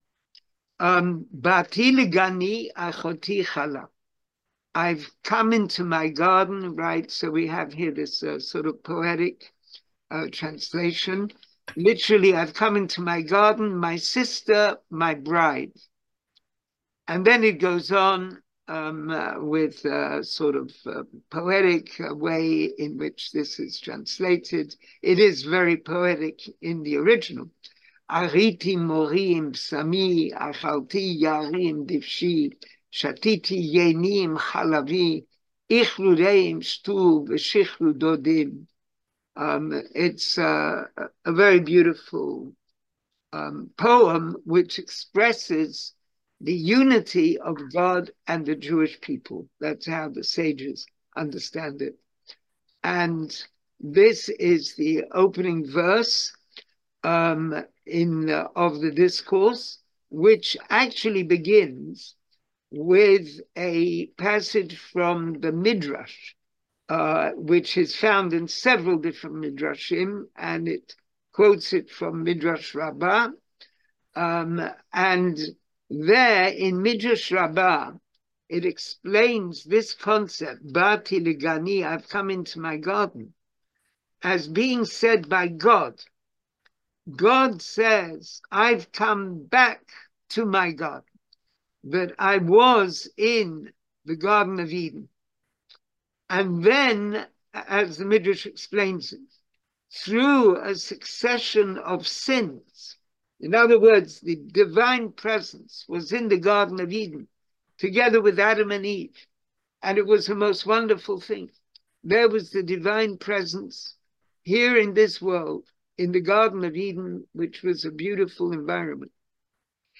Class audio Listen to the class Watch the Video Class material Hebrew Summary of Discourse English Summary of Discourse Join the class?